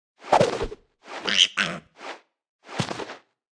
Media:Sfx_Anim_Super_Hog Rider.wavMedia:Sfx_Anim_Ultra_Hog Rider.wavMedia:Sfx_Anim_Ultimate_Hog Rider.wav 动作音效 anim 在广场点击初级、经典、高手、顶尖和终极形态或者查看其技能时触发动作的音效
Sfx_Anim_Baby_Hog_Rider.wav